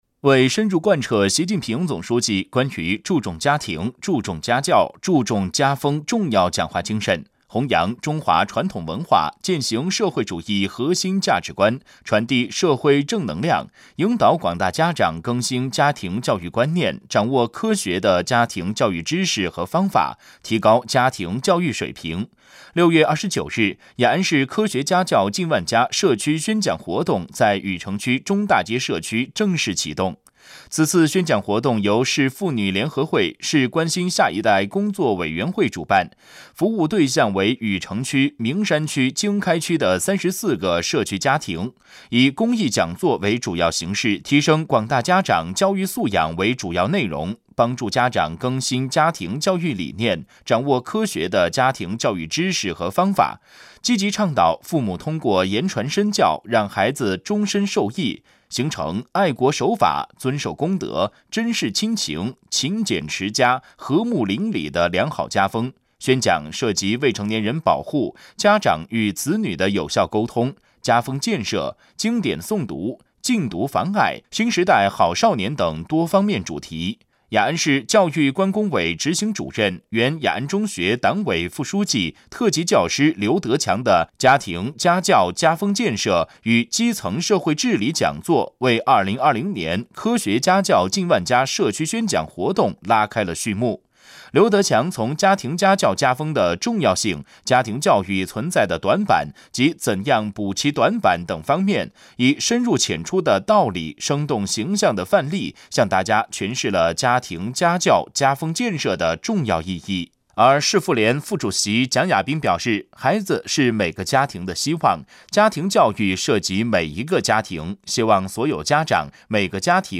雅安市广播电视台